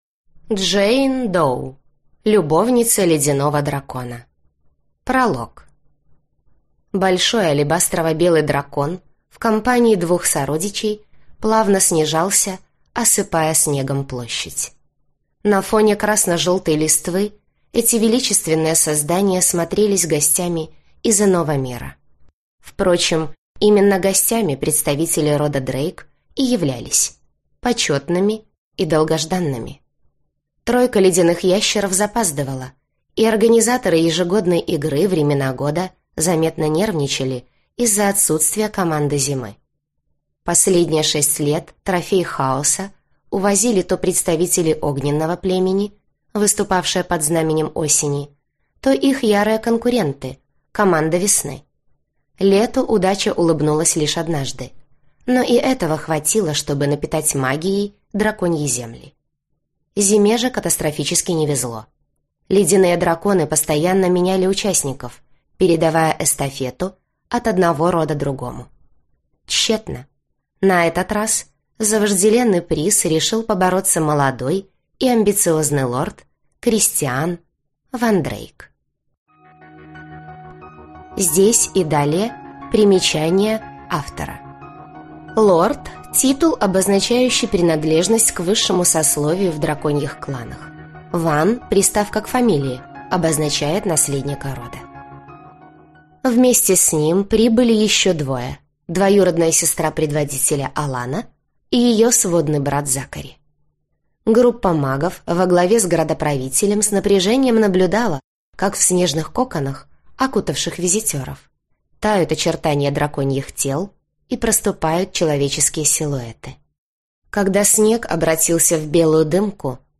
Аудиокнига Любовница ледяного дракона | Библиотека аудиокниг